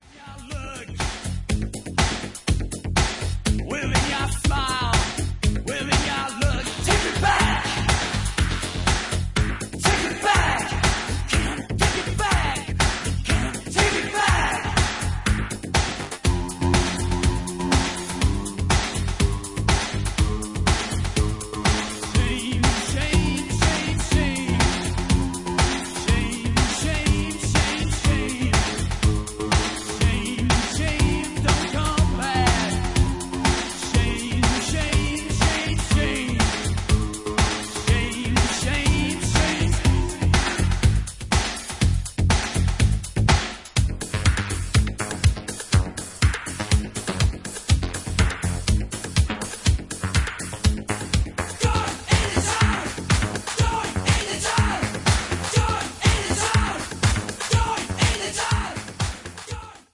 Industrial meets techno!